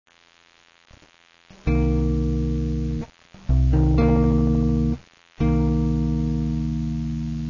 pour les accords mineur7 ou mineur7b5:
Si vous n'avez pas déjà capté..., en cliquant sur les tablatures, vous aurez l'illustration sonore (en C).
On utilise la main droite exactement de la même manière que sur une guitare acoustique: le pouce pour les notes graves, et l'index et majeur pour les notes aiguës sans buté mais simplement en pinçant.
accordmineur7-1.WAV